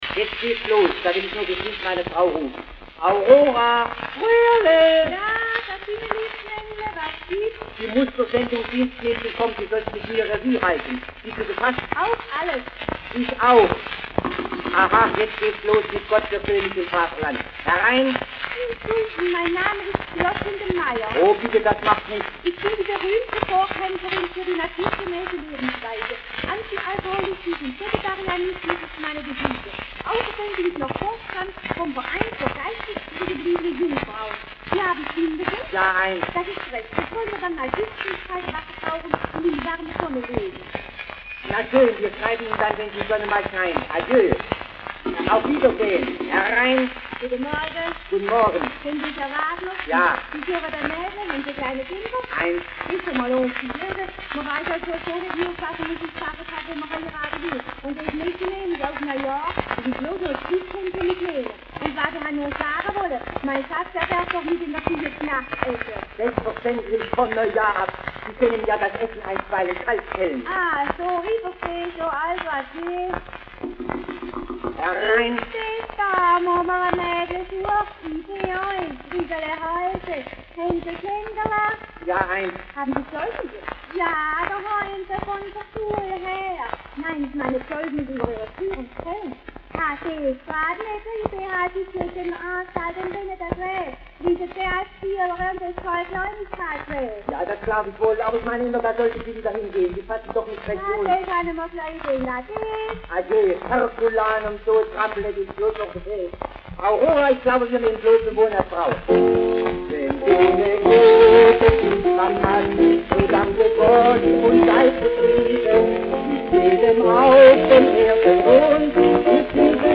Schellackplattensammlung